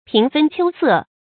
注音：ㄆㄧㄥˊ ㄈㄣ ㄑㄧㄡ ㄙㄜˋ
平分秋色的讀法